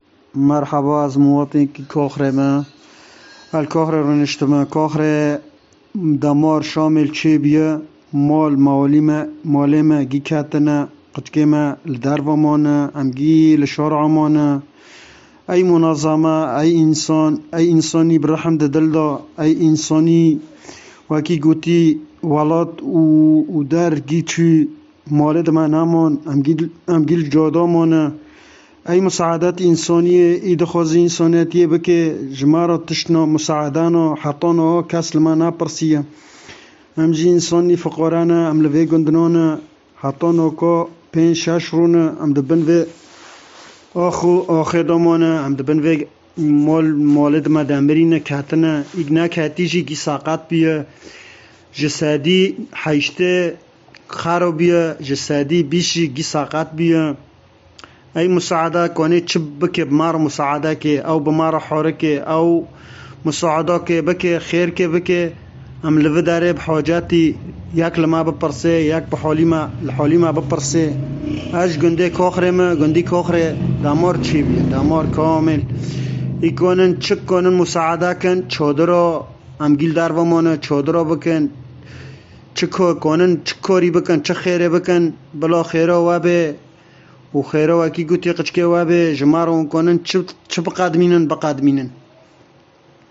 قصص صادمة في تسجيلات صوتية وفيديوهات حصرية أرسلها عدد من سكان قرية كاخري بريف عفرين إلى منصة تارجيت الإعلامية
فيما تحدثت سيدة من أهالي القرية المنكوبة، عن فقدان حليب الأطفال ونفاذ المواد الغذائية شيئاً فشيئاً، إلى جانب بقاء معظم العائلات التي دُمرت منازلهم جراء الزلزال في العراء منذ أيام، وتابعت: “والله عم نموت من البرد والجوع لوين بدنا نروح”.